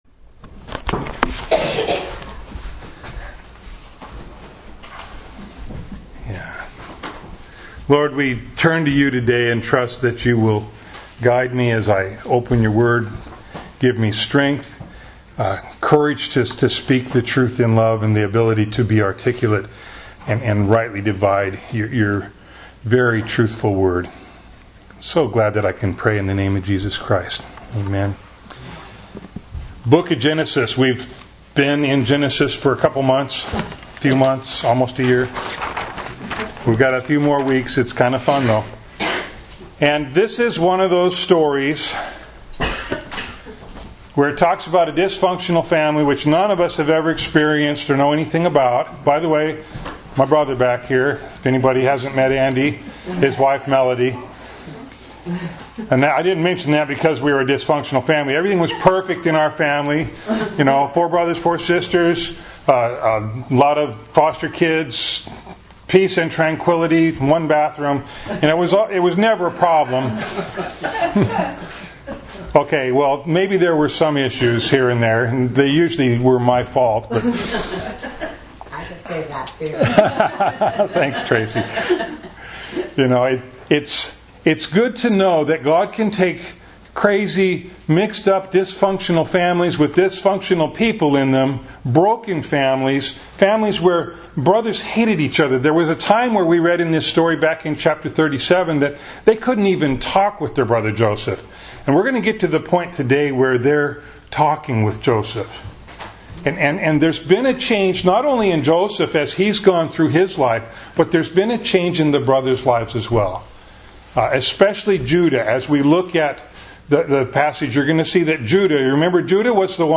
Bible Text: Genesis 43:1-45:28 | Preacher